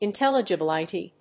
音節in・tel・li・gi・bil・i・ty 発音記号・読み方
/ɪntèlədʒəbíləṭi(米国英語)/